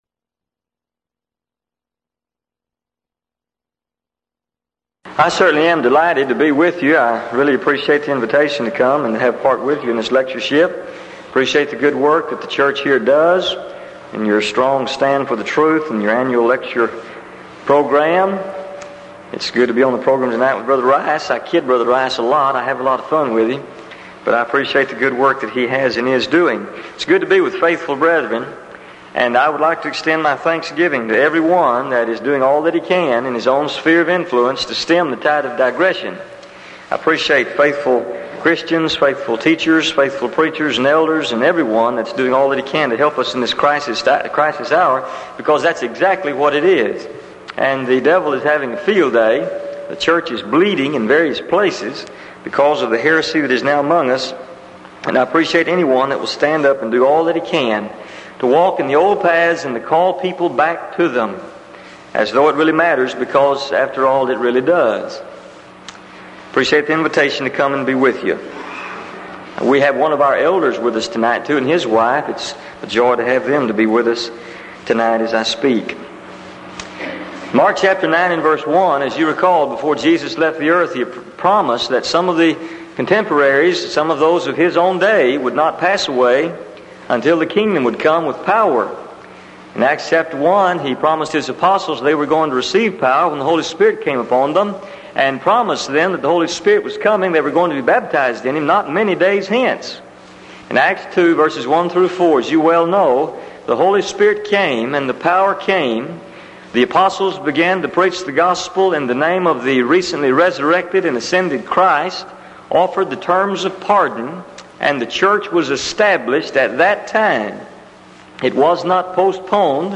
Event: 1985 Denton Lectures
lecture